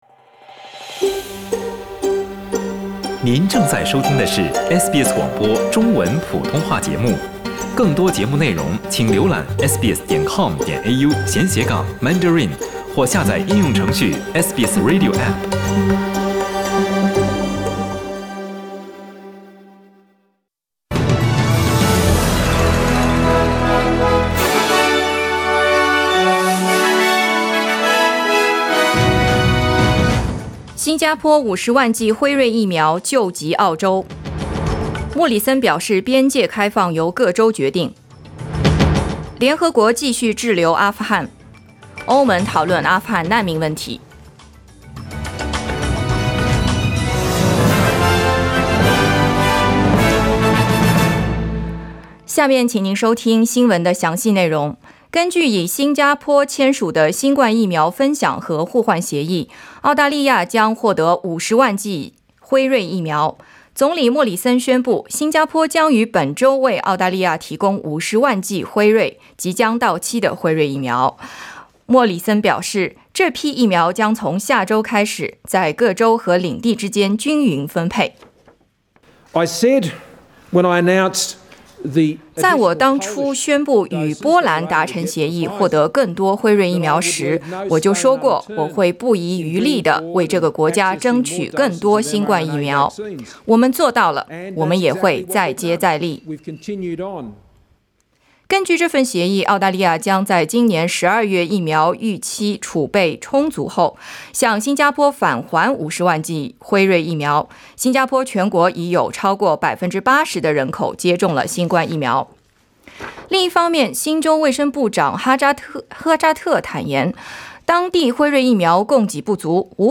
SBS早新聞 （9月1日）
SBS Mandarin morning news Source: Getty Images